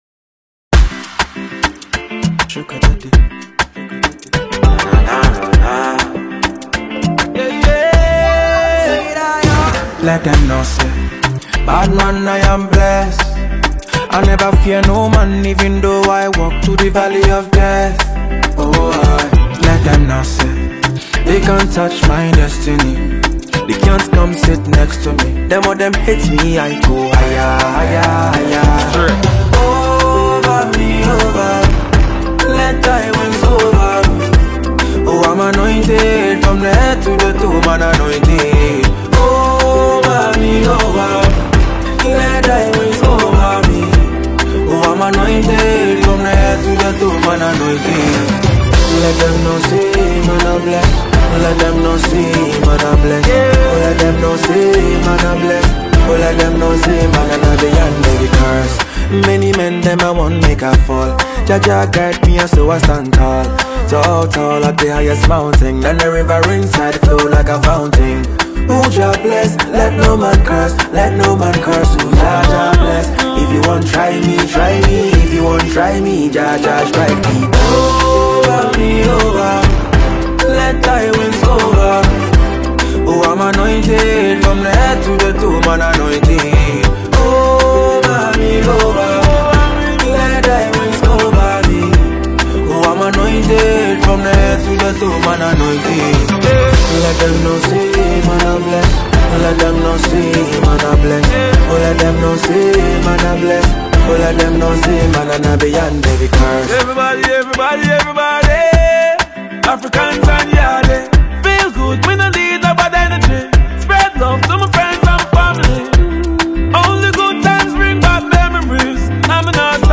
Ghanaian highlife and afrobeat singer-songwriter
Jamaican dancehall singer